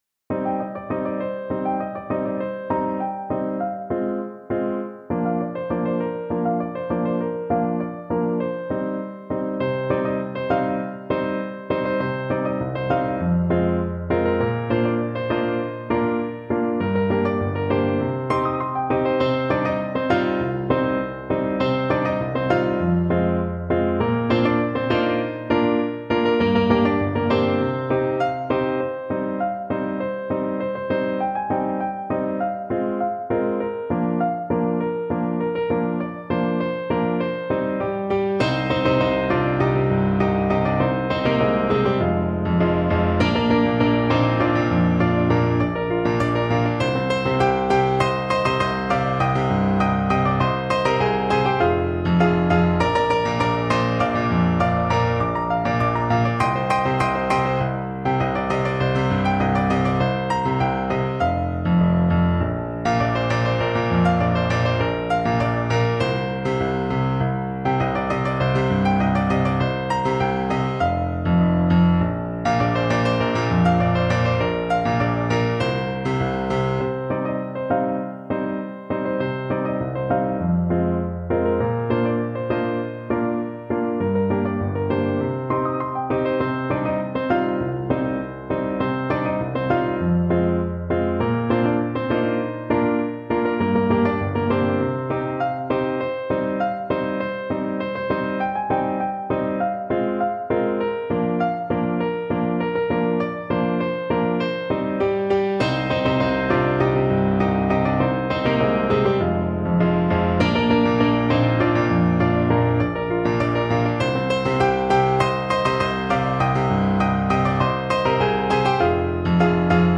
Ноты для фортепиано.
*.mid - МИДИ-файл для прослушивания нот.